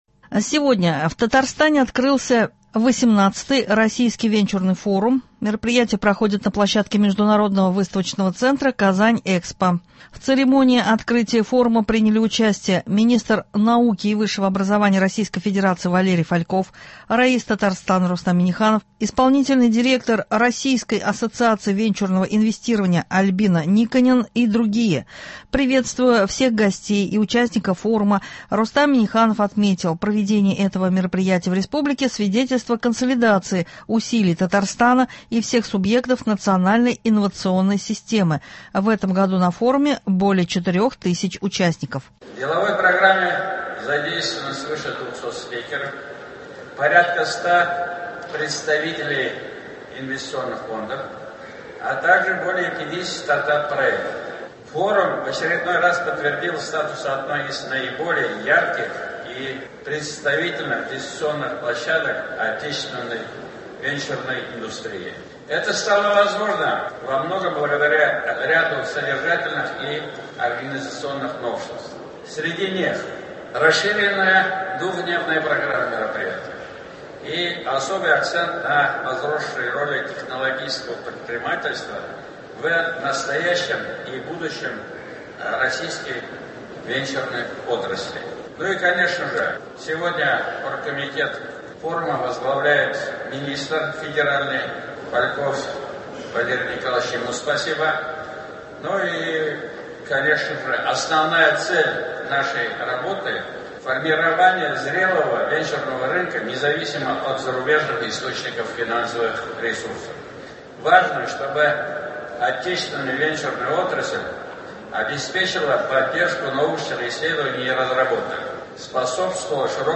Новости (25.04.24)